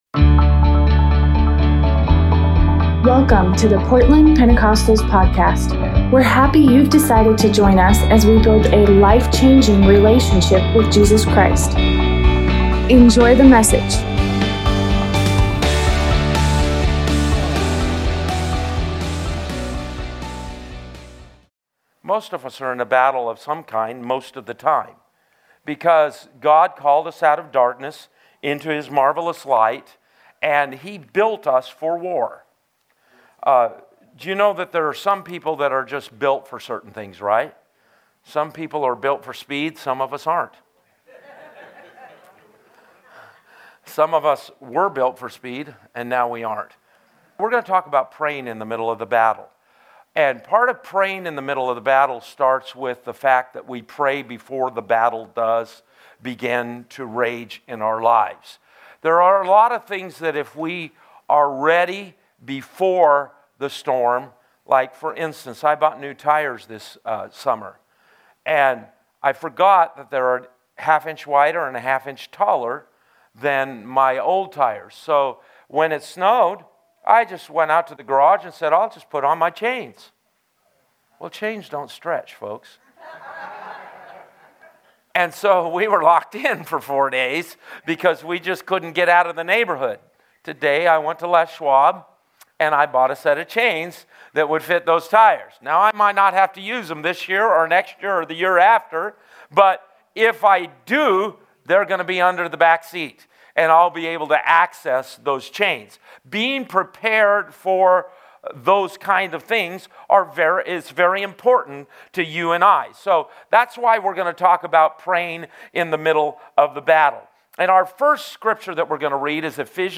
Tuesday Bible study